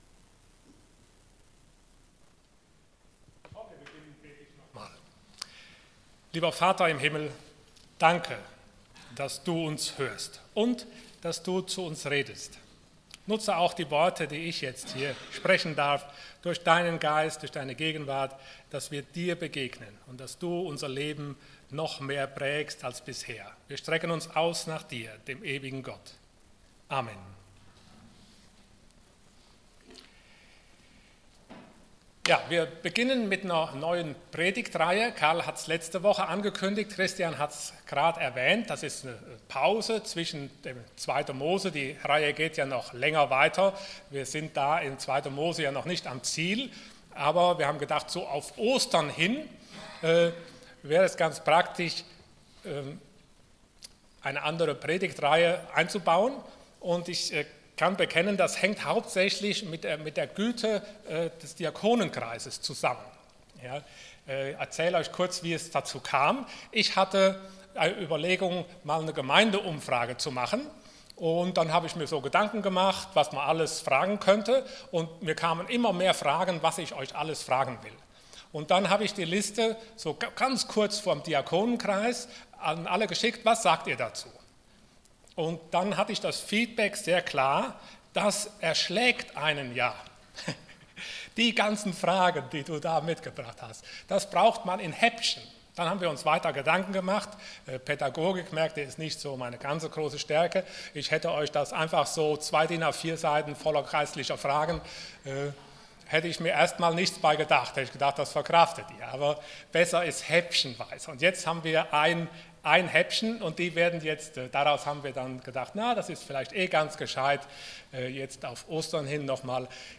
Dienstart: Sonntag Morgen Wie rede ich mit meinem Vater im Himmel? Themen: Beziehung , Familie , Gebet « ER ermöglicht Begegnung Wie höre ich meinen Vater im Himmel?